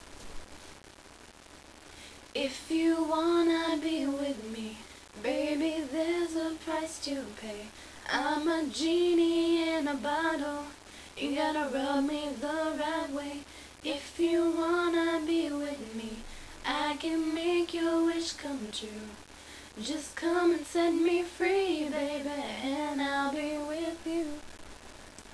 Please Note...they Do NOT Play Instruments